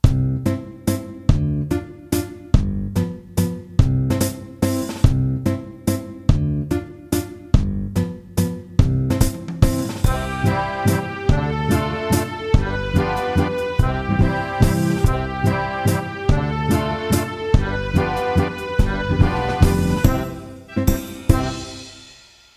First of a pair of Italian style Waltzes.